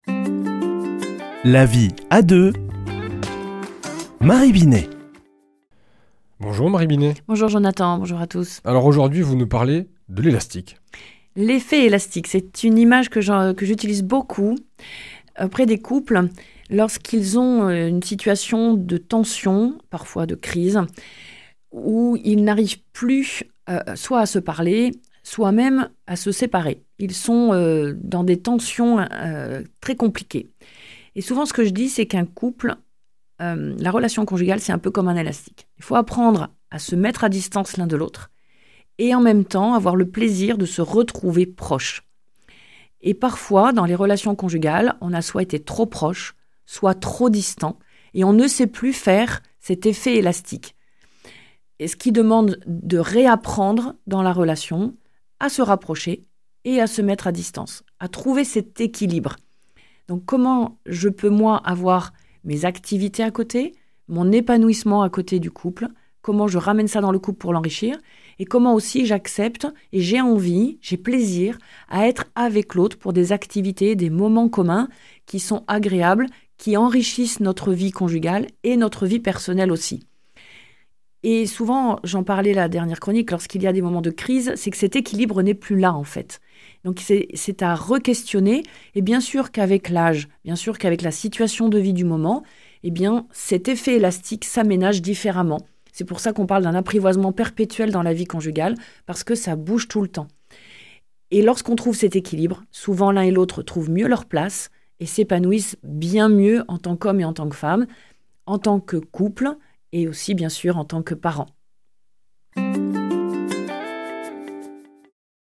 mardi 10 décembre 2024 Chronique La vie à deux Durée 4 min